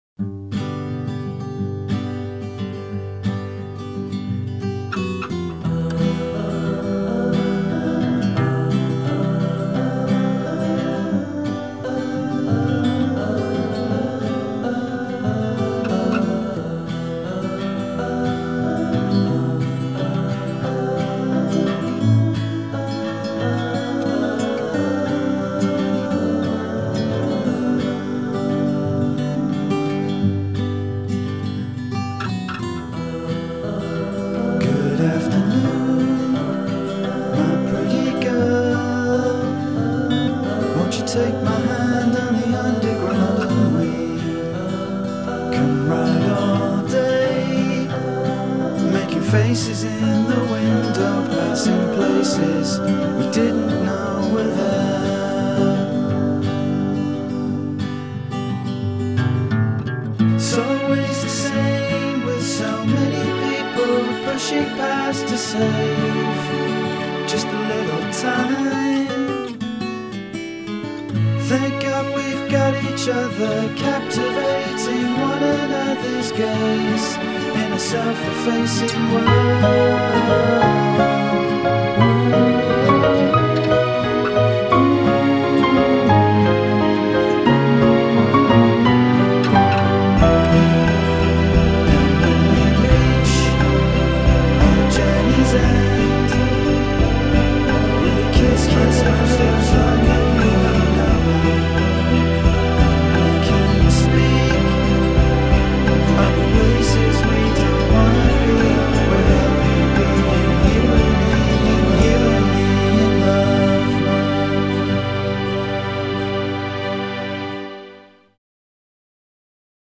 * Demo *